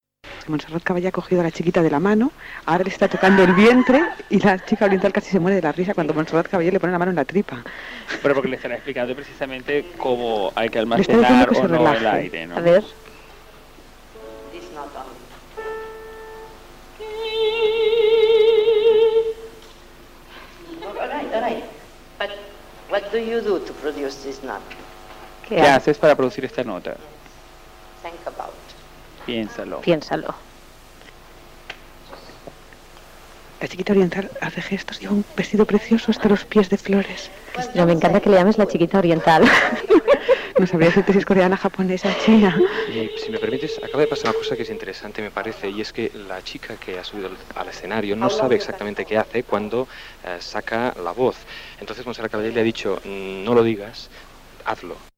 Montserrat Caballé fa una prova a una cantant d'òpera Gènere radiofònic Entreteniment
Programa presentat per Gemma Nierga